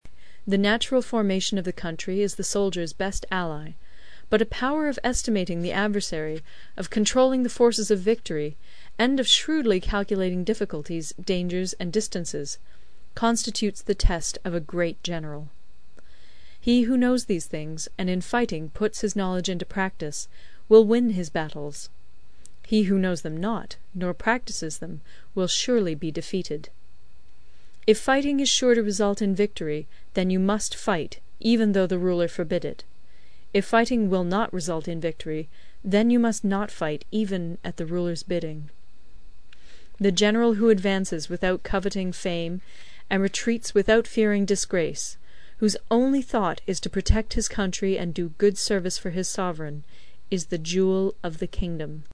有声读物《孙子兵法》第59期:第十章 地形(3) 听力文件下载—在线英语听力室